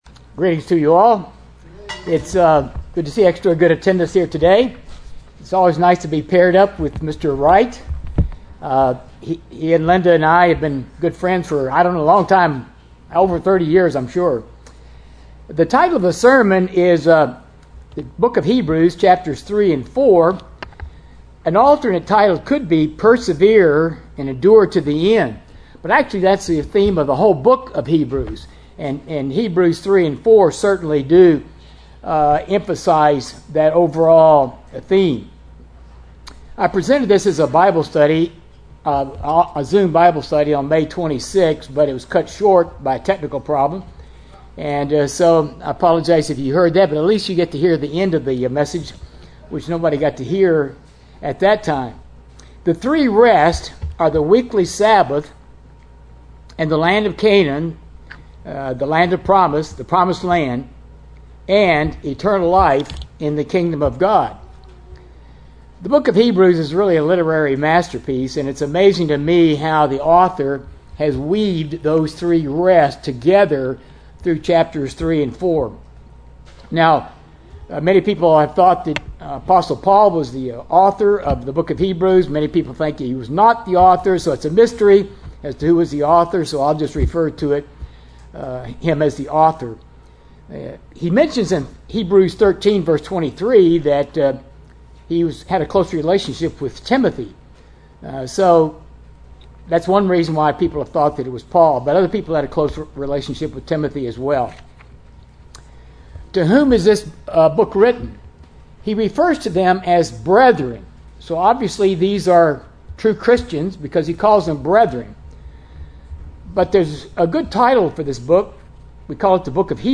Sermons
Given in Fort Worth, TX